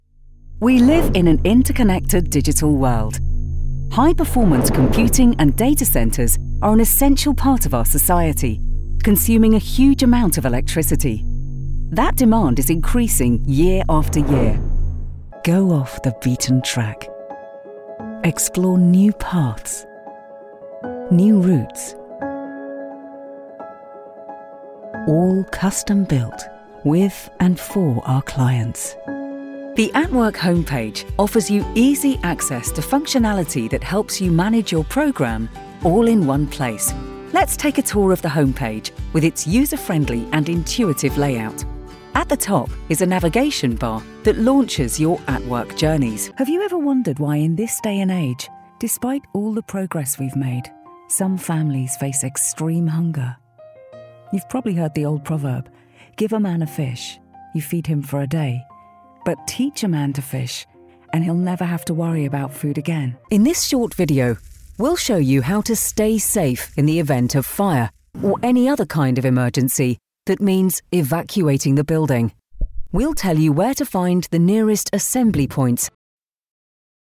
Inglés (Reino Unido)
Mujer
Vídeos corporativos